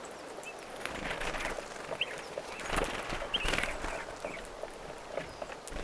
tuollaisia punatulkkulinnun ääniä kaksi
tuollaisia_punatulkun_aania_kaksi.mp3